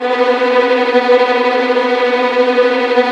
Index of /90_sSampleCDs/Roland LCDP08 Symphony Orchestra/STR_Vls Tremolo/STR_Vls Trem wh%